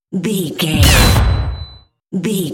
Dramatic hit metal electricity
Sound Effects
heavy
intense
dark
aggressive